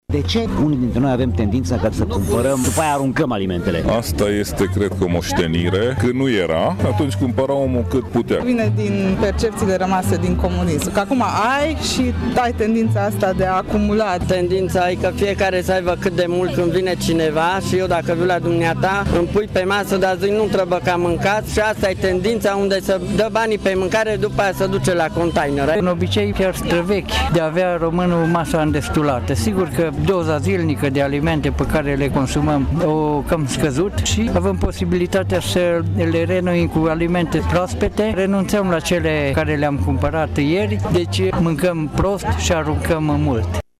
Opiniile tg.mureșenilor în privința tendinței românilor de a cumpăra multe alimente și de a le arunca apoi sunt împărțite.